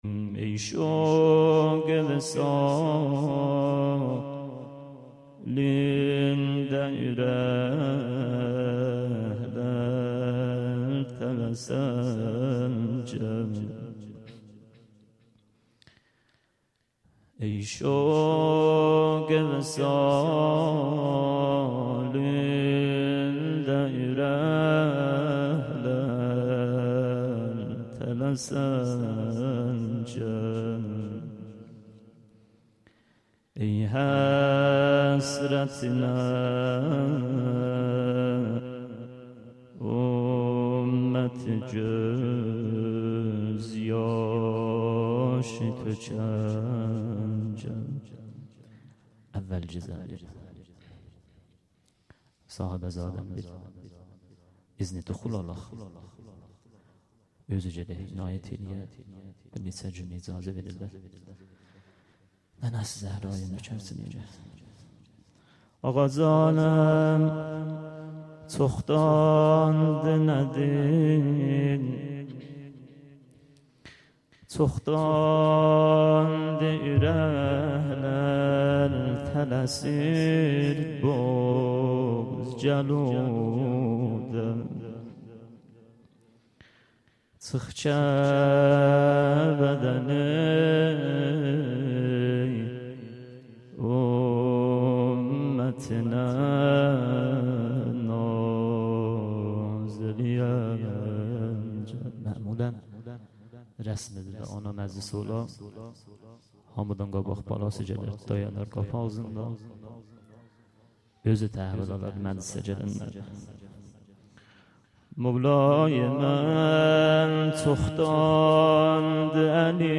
هیأت محبان اهل بیت علیهم السلام چایپاره - فاطمیه 97 ( اول ) - شب اول - مقدمه و روضه